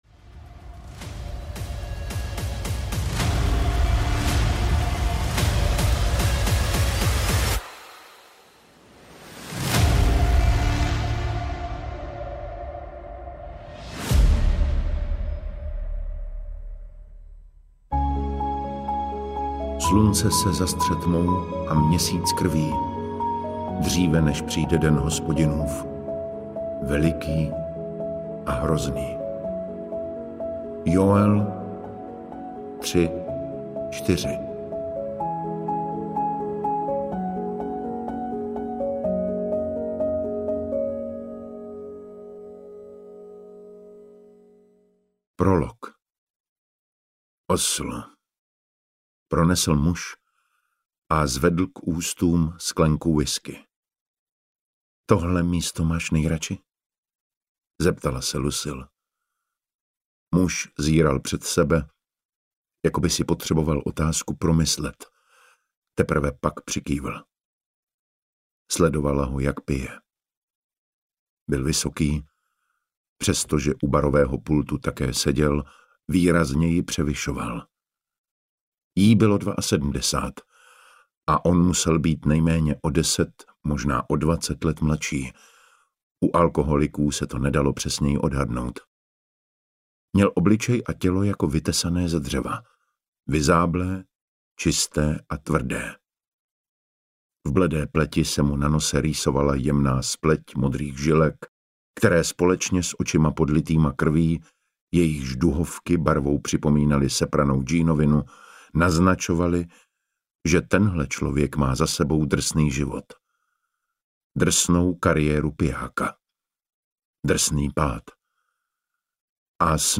Zatmění audiokniha
Ukázka z knihy
• InterpretDavid Matásek